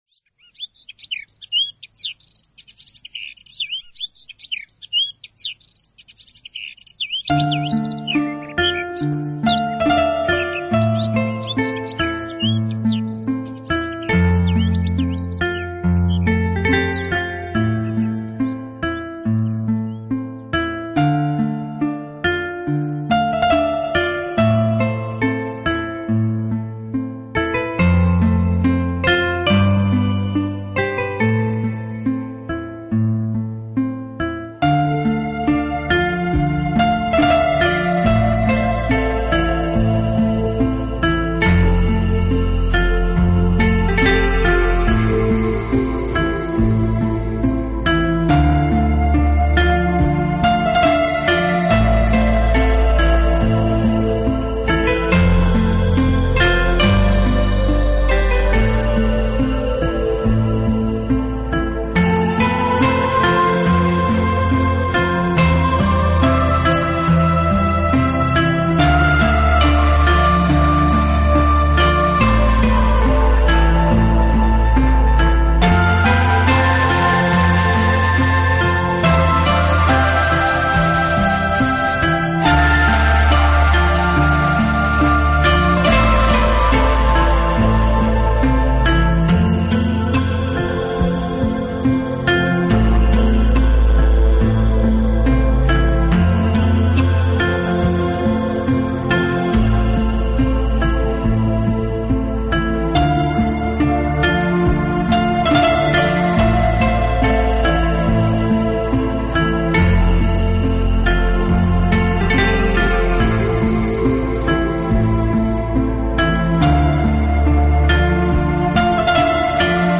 在音乐的安静里慢慢舒适和沉静